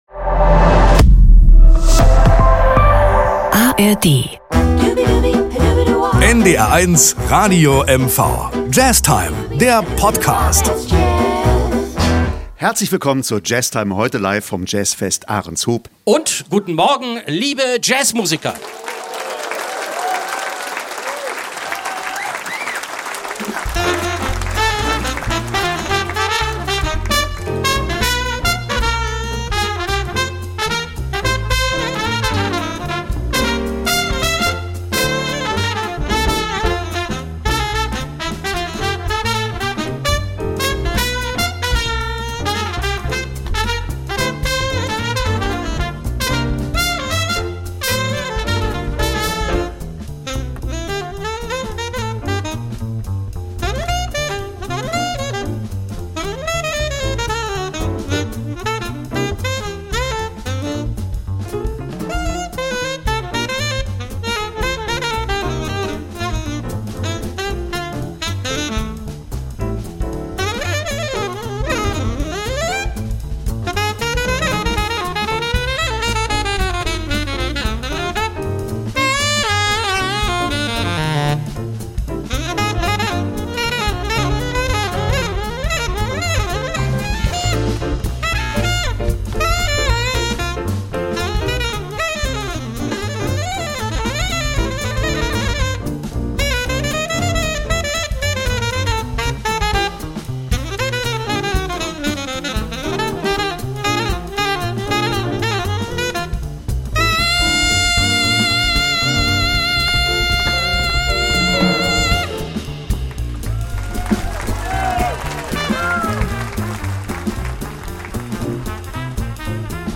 Sie hören Ausschnitte aus dem Konzert vom 21. Juni 2025
Trompete
Flügelhorn
Bass
Drums